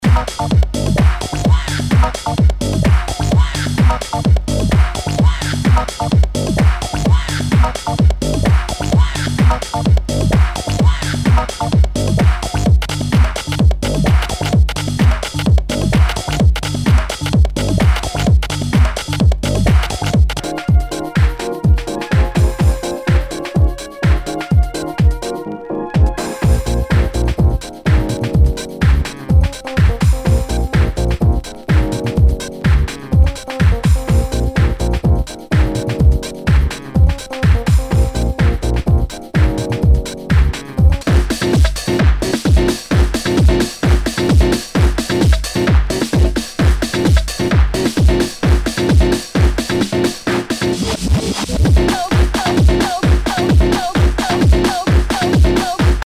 HOUSE/TECHNO/ELECTRO
デトロイト・テクノ / ハウス・クラシック！
全体にチリノイズが入ります。